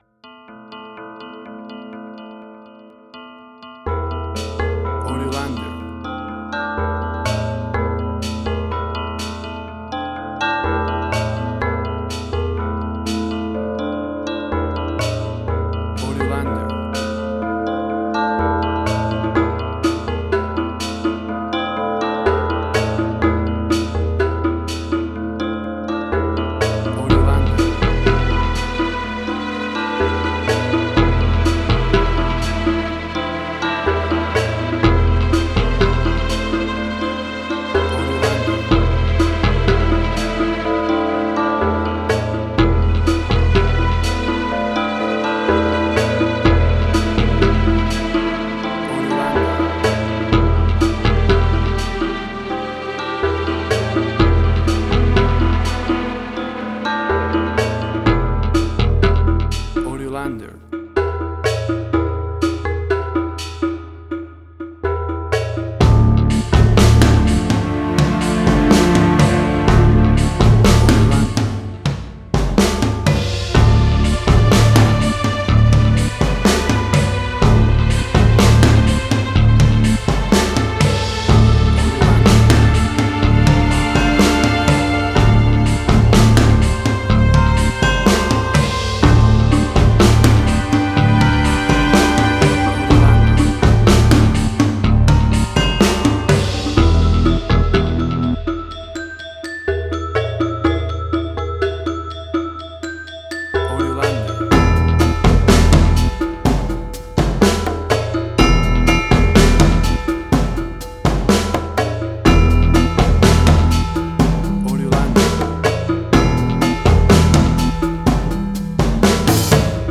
Gamelan Ethnic instrumental.
Tempo (BPM): 124